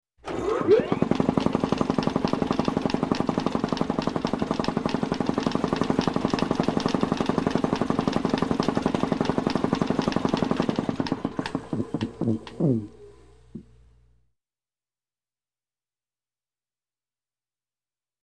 Compressor
Motorized Shop Tool, Compressor Motor 5; Turn On, Steady Chugging Motor, And Off.